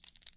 描述：骨头紧缩。
Tag: 紧缩 声音